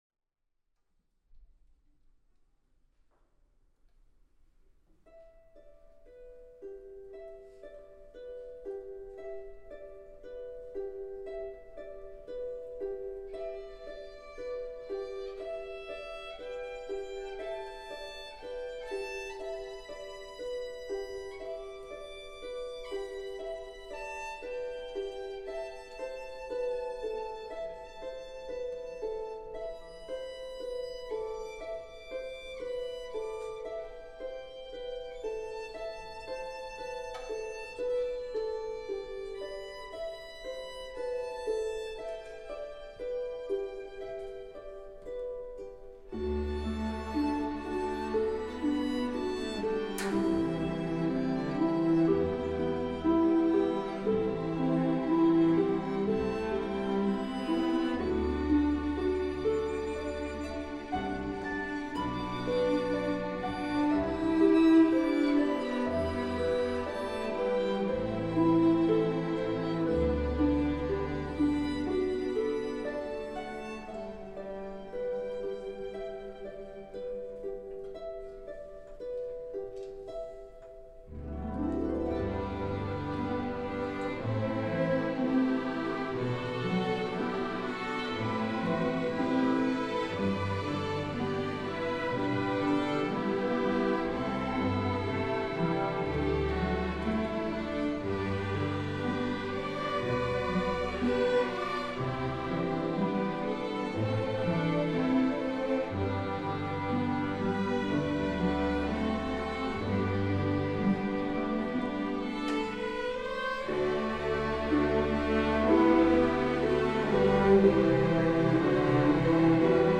Orchestra Recordings